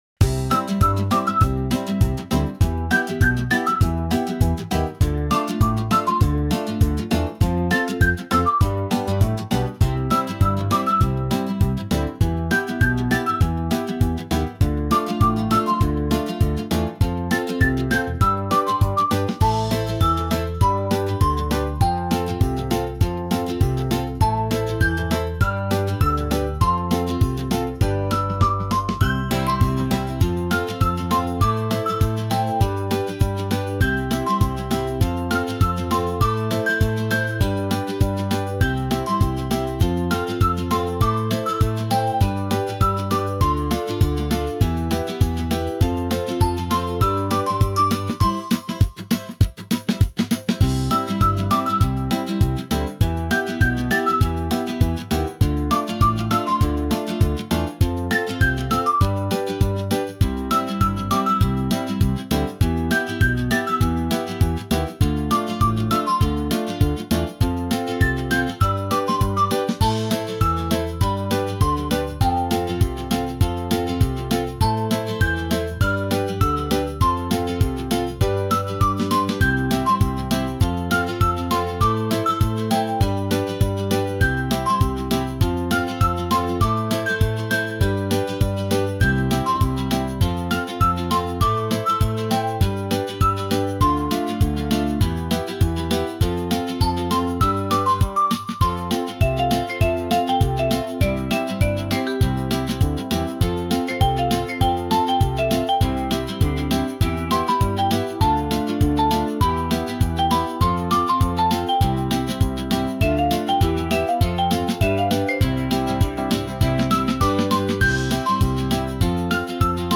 キッズ＆ハッピーポップ・インストゥルメンタル・ボーカル無し
明るい 元気